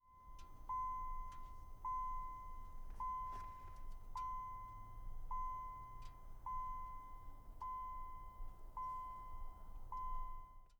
Car_ Internal_Warning-Ding_Plymouth-Acclaim
acclaim car ding interal plymouth warning sound effect free sound royalty free Sound Effects